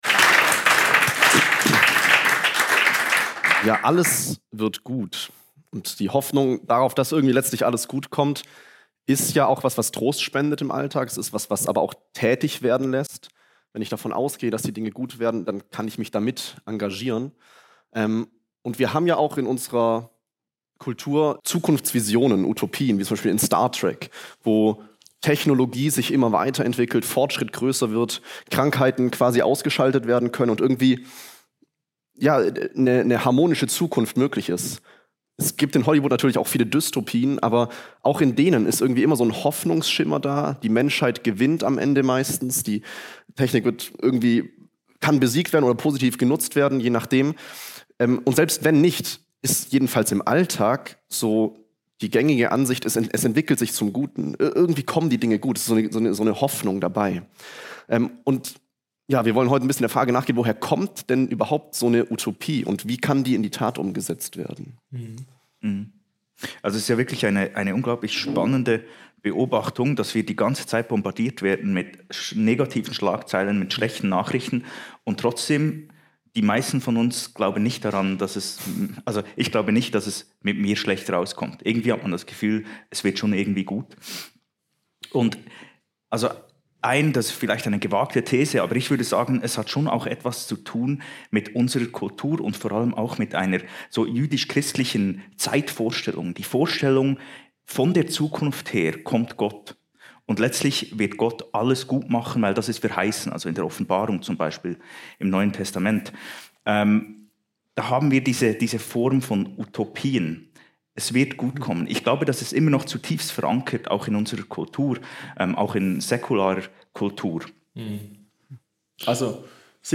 Das Reich Gottes ist kein Gottesstaat [Live @ RefLab Festival]